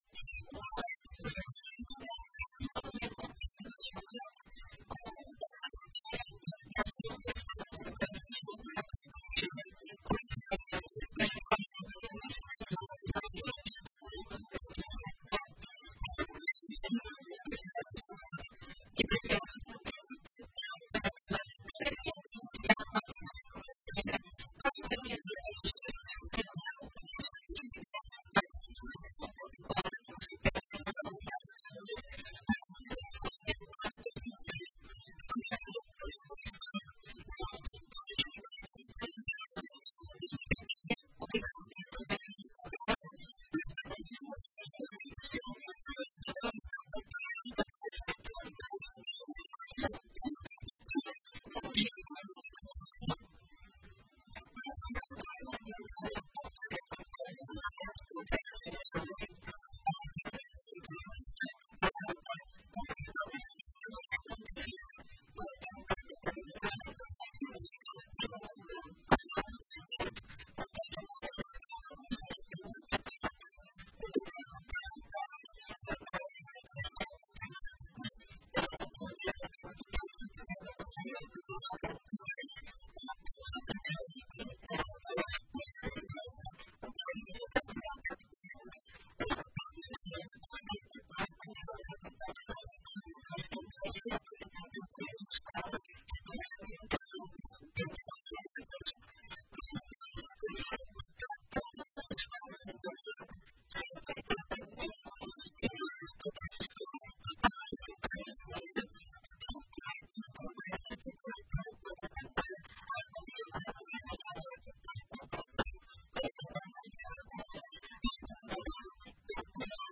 Em entrevista à RPI, secretária de Educação do RS, Raquel Teixeira, fala sobre investimentos e desafios para 2025
Ouça abaixo a entrevista completa realizada no programa Rádio Ligado nesta sexta-feira: